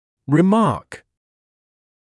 [rɪ’mɑːk][ри’маːк]ремарка, замечание